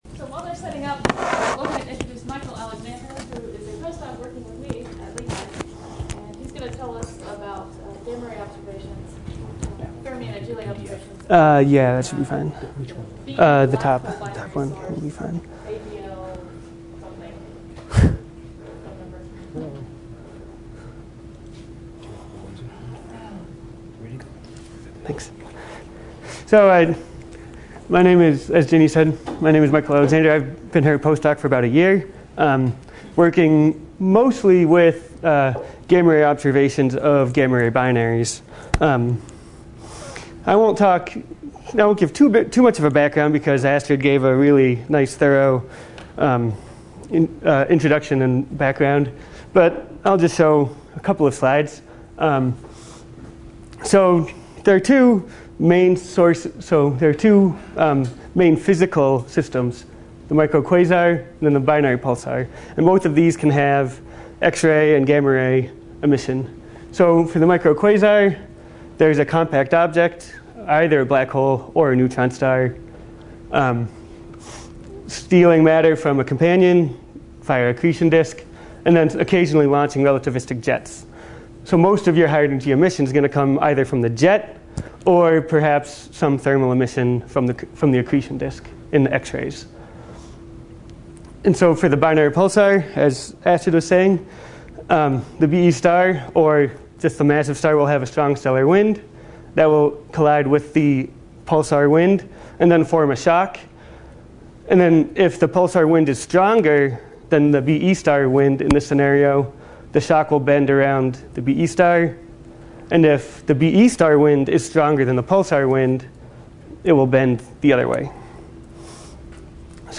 11 Aug morning invited talk